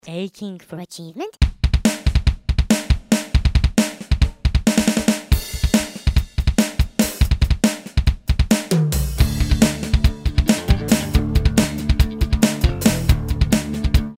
Genre: blend of rock and Greek folk
Rhythm: Greek folk rhythm 9/8